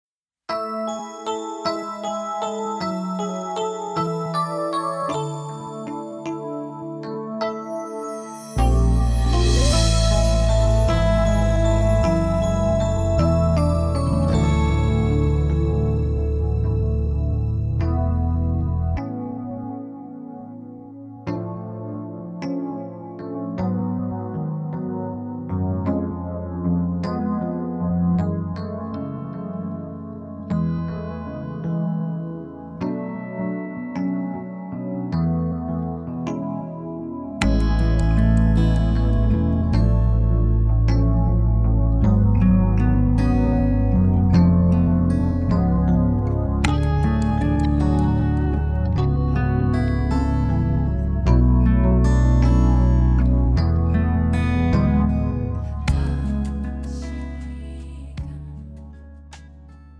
음정 -1키
장르 가요 구분 Premium MR